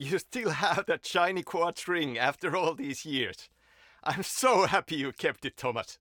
Alan Wake 2 test audio with Sam Lake.
This file is an audio rip from a(n) Windows game.